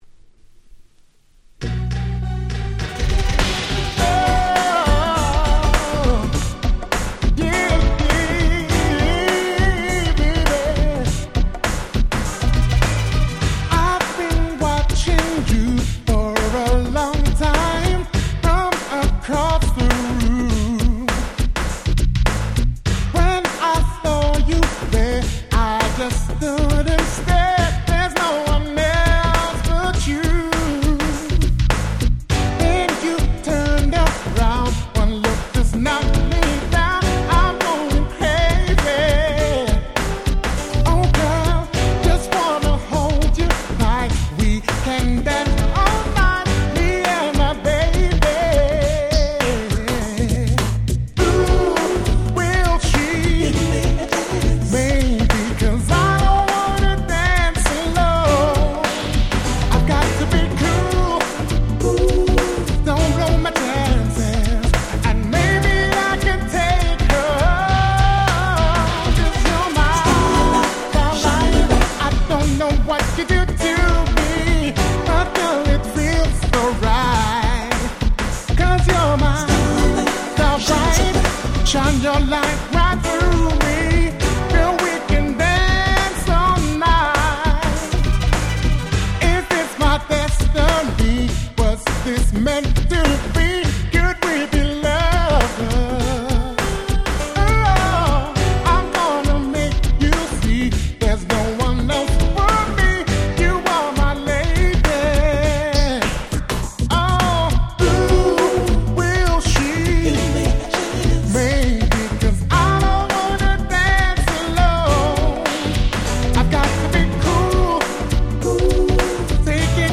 【Media】Vinyl 12'' Single
93' Very Nice UK R&B !!
90's R&B